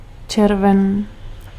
Ääntäminen
Ääntäminen France (Paris): IPA: [ʒy.ɛ̃] France (Avignon): IPA: /ʒɥɛ̃/ Tuntematon aksentti: IPA: /ʒy.œ̃/ Haettu sana löytyi näillä lähdekielillä: ranska Käännös Ääninäyte Substantiivit 1. červen {m} Suku: m .